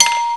SOUND\BELL3.WAV